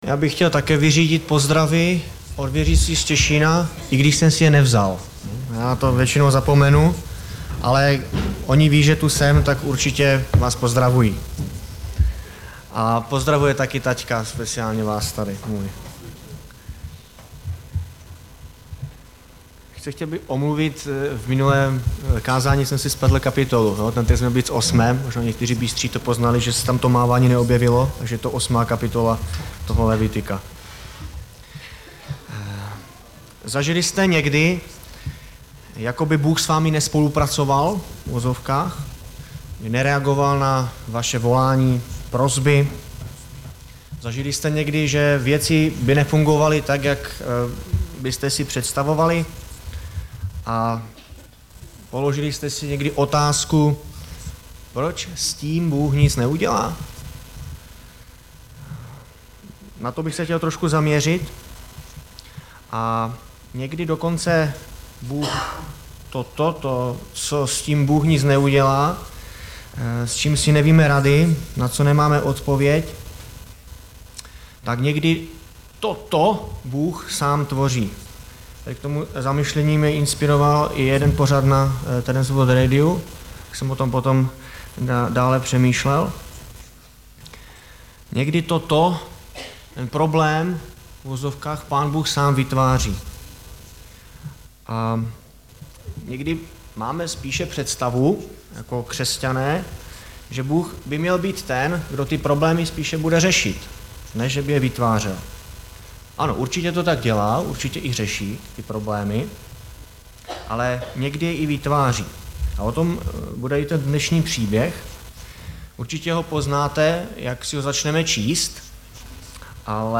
Nedělní vyučování